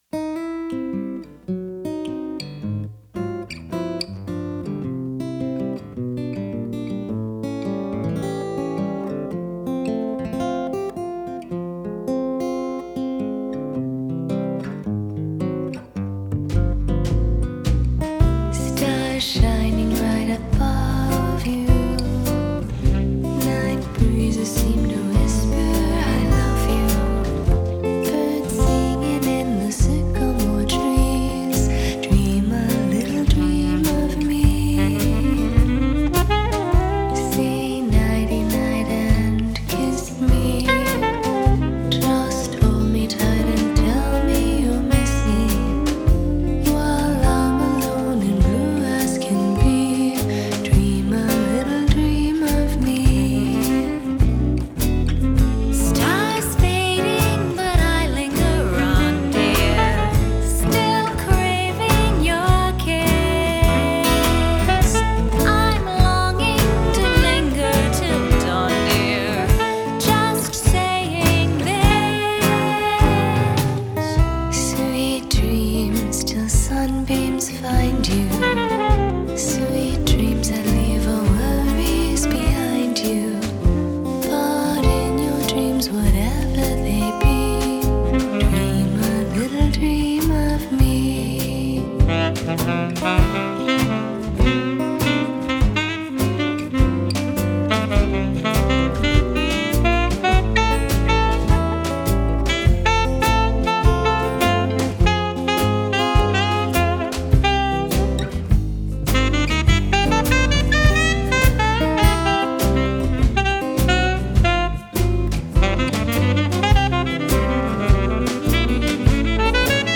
standup bass, piano, guitar, vocals
drums, percussion.
Genre: Jazz Vocals, Gipsy Swing